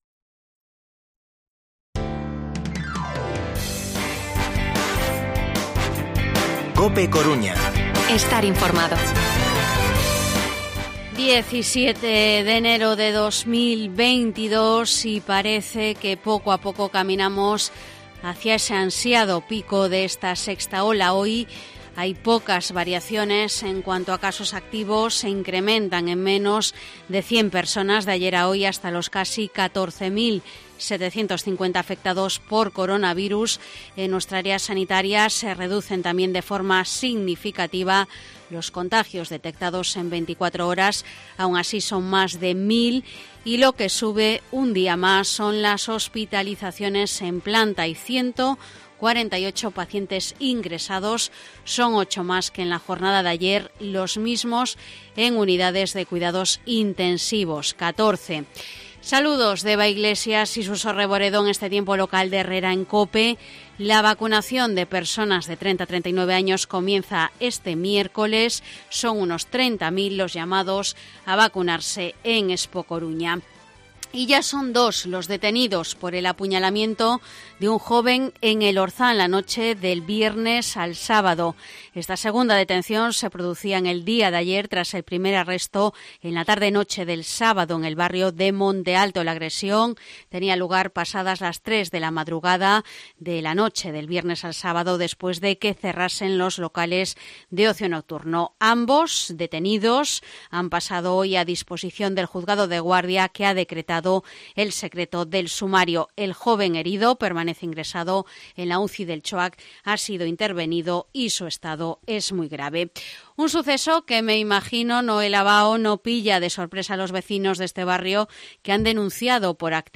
Detenidos por apuñalamiento joven Orzán (hablan vecinos).